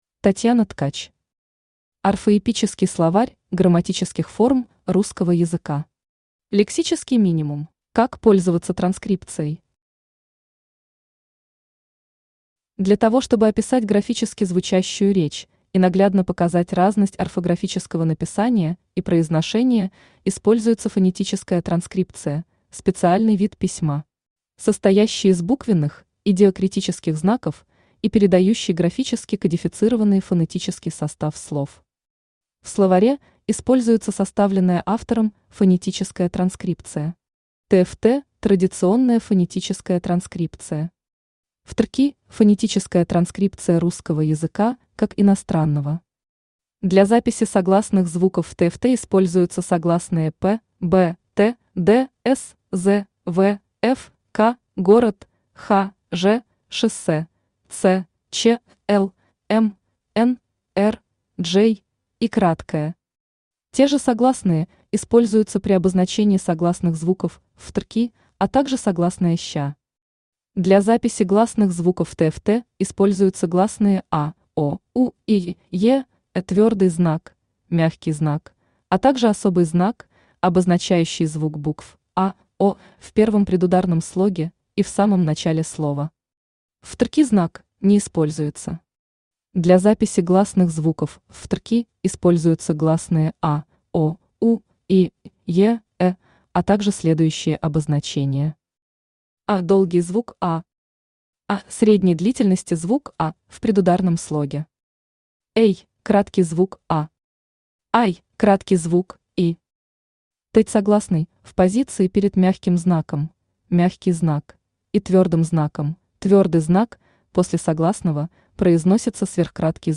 Аудиокнига Орфоэпический словарь грамматических форм русского языка. Лексический минимум | Библиотека аудиокниг
Лексический минимум Автор Татьяна Григорьевна Ткач Читает аудиокнигу Авточтец ЛитРес.